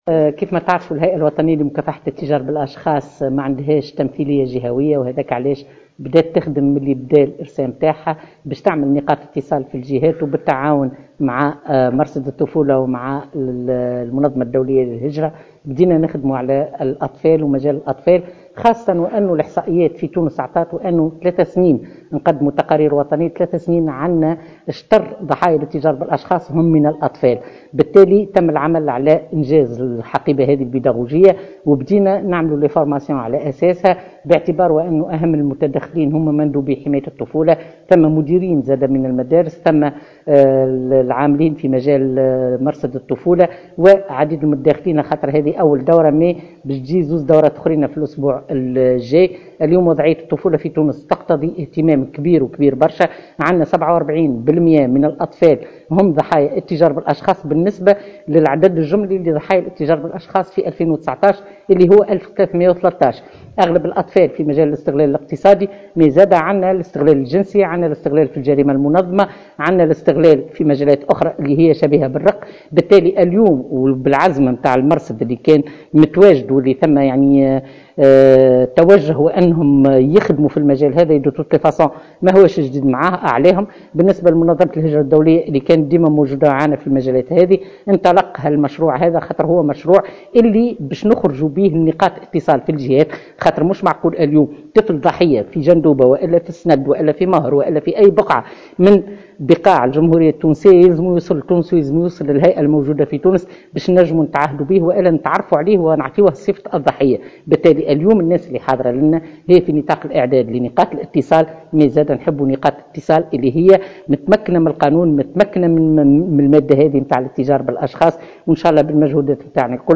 أكثر تفاصيل في تصريح روضة العبيدي رئيسة الهيئة الوطنية لمكافحة الاتجار بالاشخاص